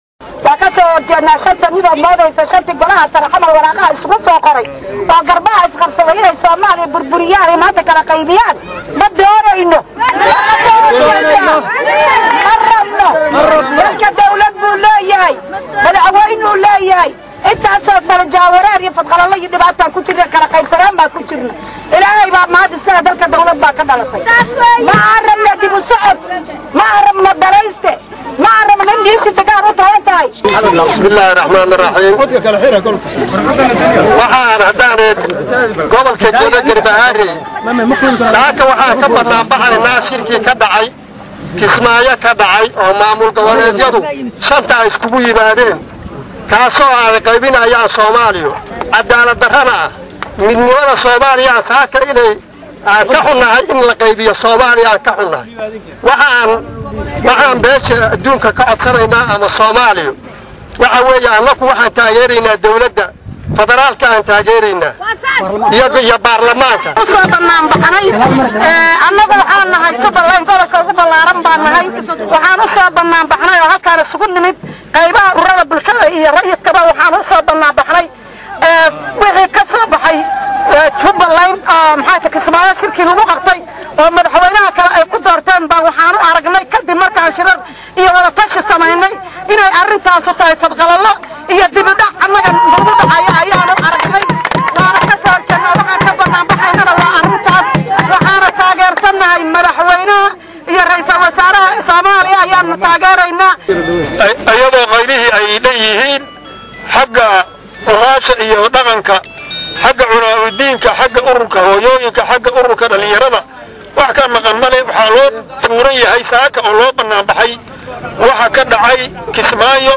Hoos Ka Dhageyso Codka Banaan baxayaasha.